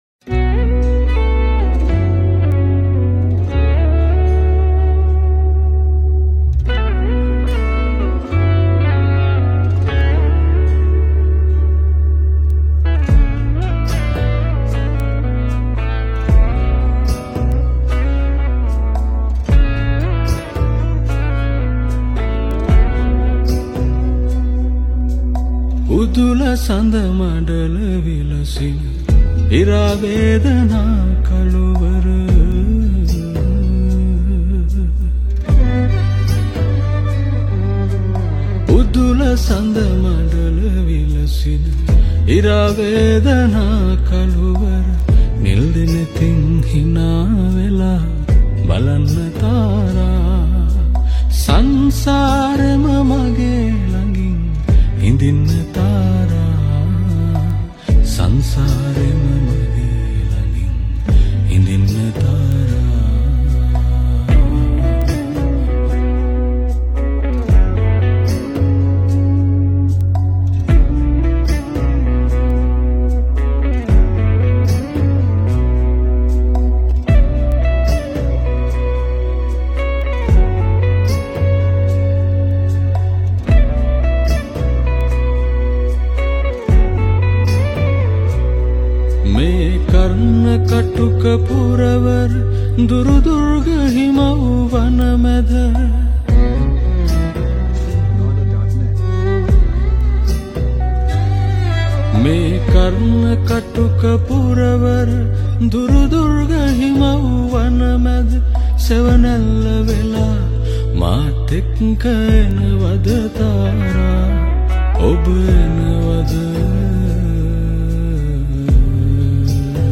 Guitar
Violin
Backing Vocals